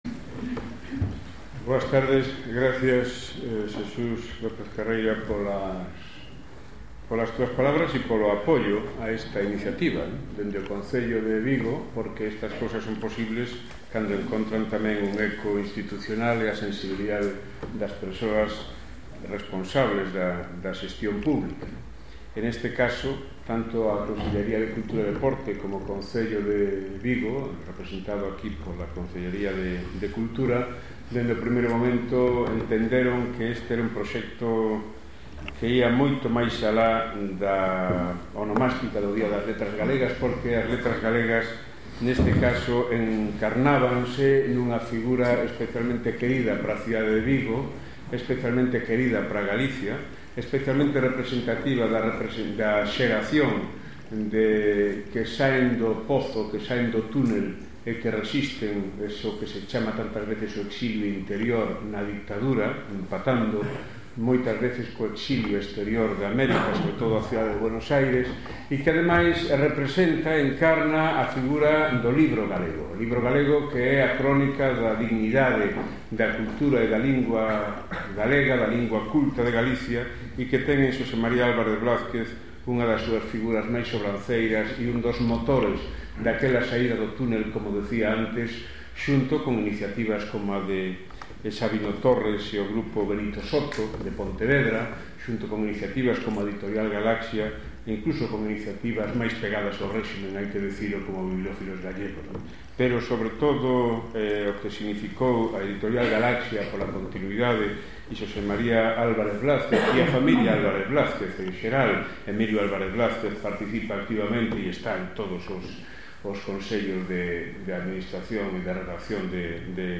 Vigo, Casa Galega da Cultura, 20 h. Acto dentro do ciclo A palabra e os días
Director Geral da editora Galaxia